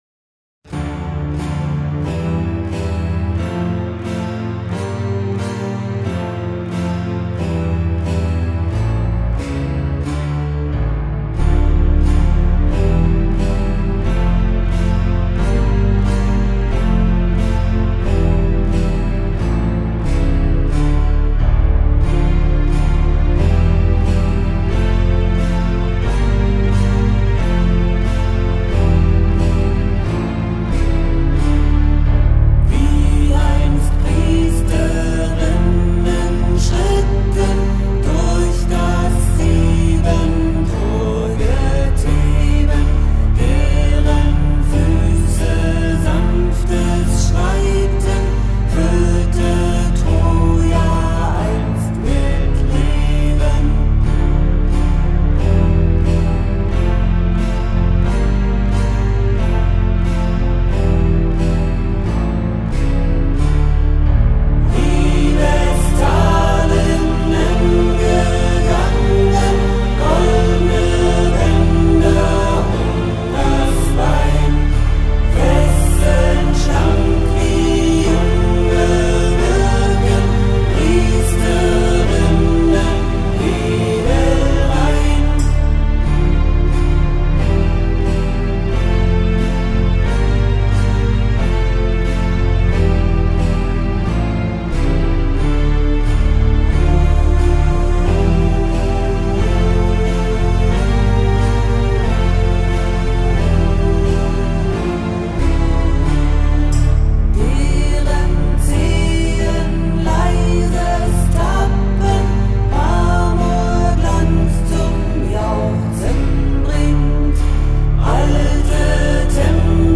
Deren Füße sanftes Schreiten (Demo) (C) 2019zur Übersicht
Gesang